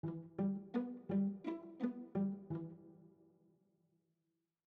RythmGame/SimpleGame/media/chords/variation2/E.mp3 at 785453b009a8a8e0f5aa8fdd36df7abdc11622fa